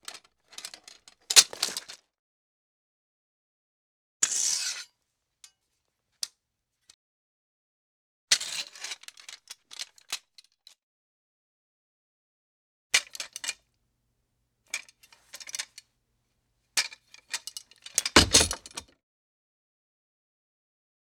weapon
Sword Handle Rattle With Scrape